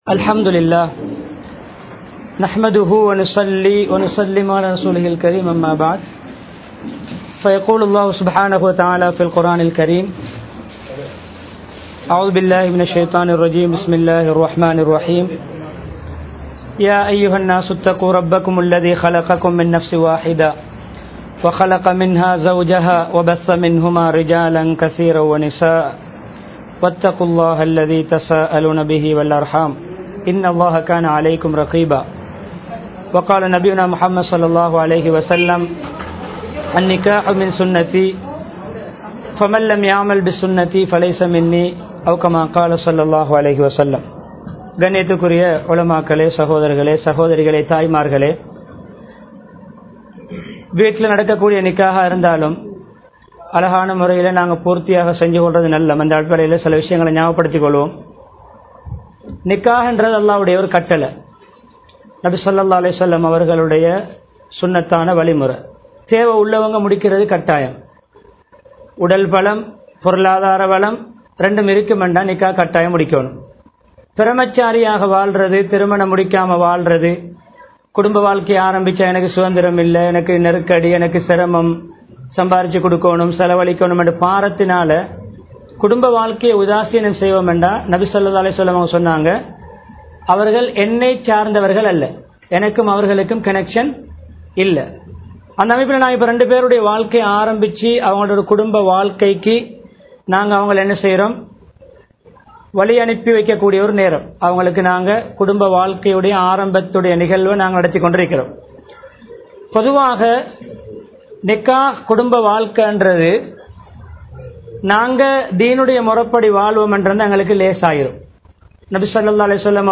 சந்தோசமான குடும்ப வாழ்க்கை | Audio Bayans | All Ceylon Muslim Youth Community | Addalaichenai